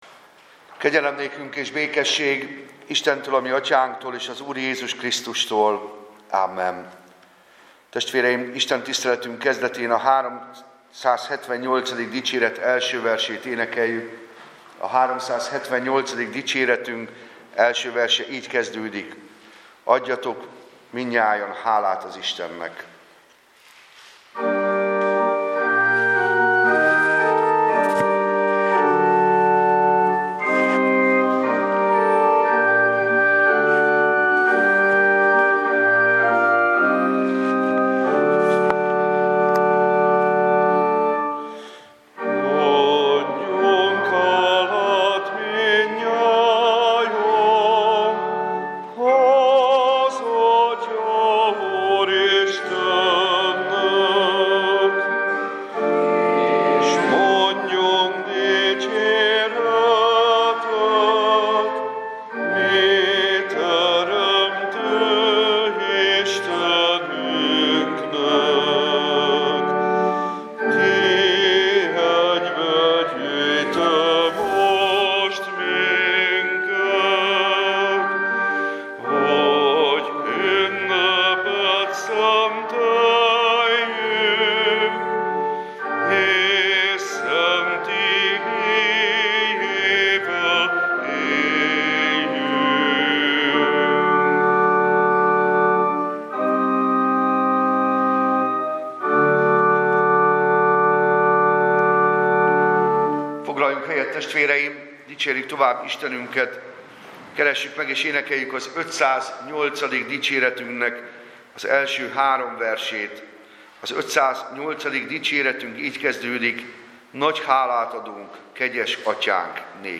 Passage: A zsidókhoz írt Levél 11. 4. Service Type: Igehirdetés